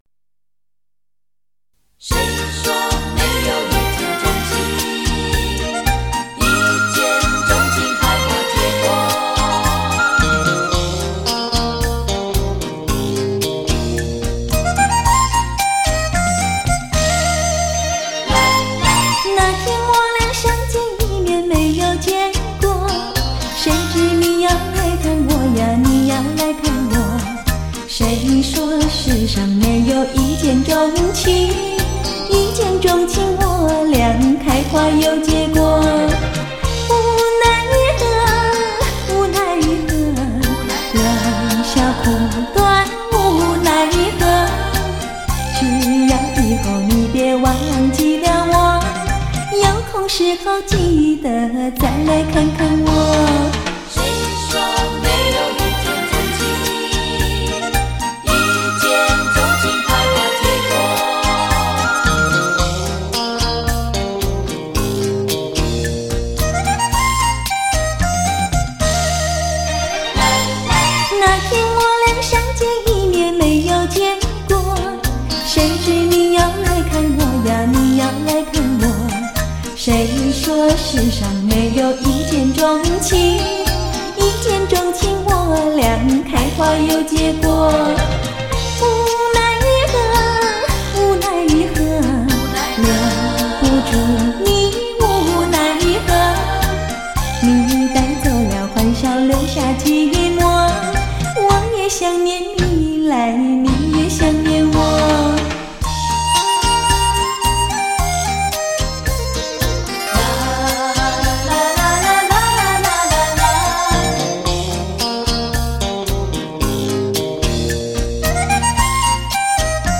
柔情金腔 穿越时空